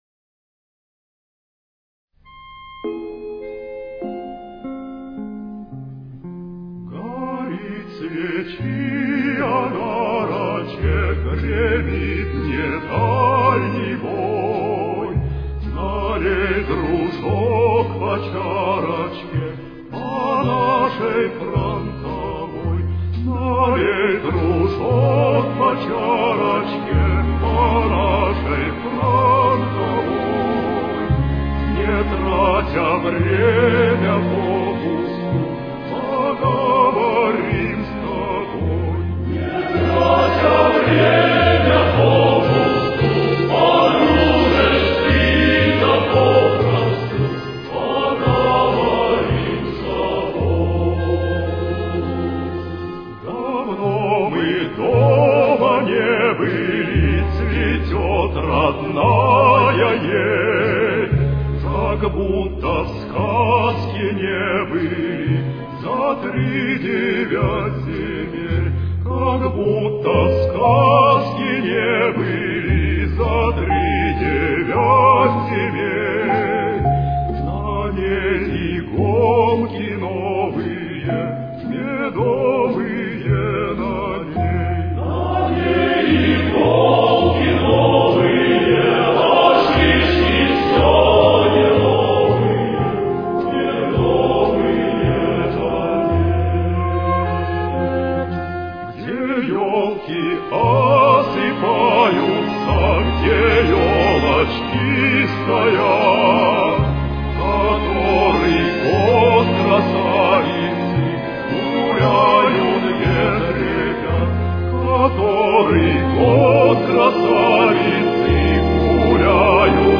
Фа минор. Темп: 57.